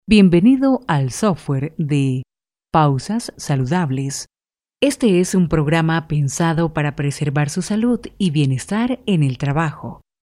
locutora, voz femenina, voz informativa, noticias, seria, grave, animados, institucional
Sprechprobe: eLearning (Muttersprache):